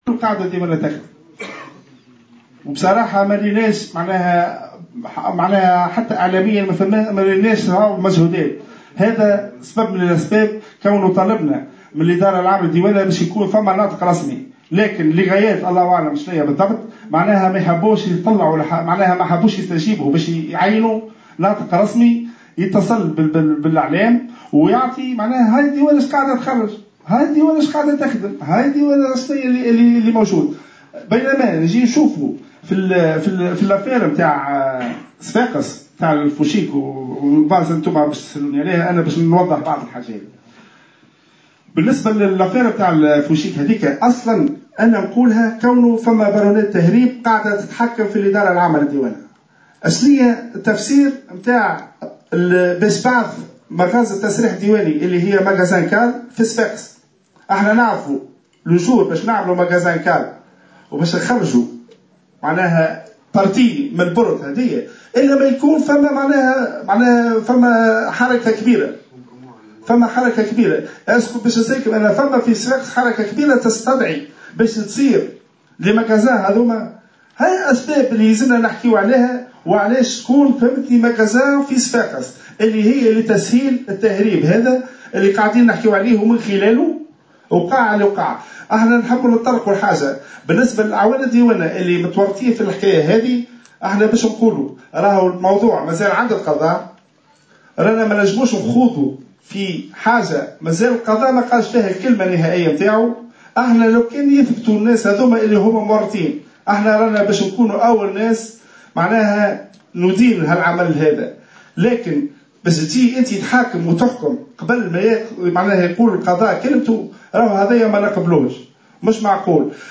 خلال ندوة صحفية اليوم الاربعاء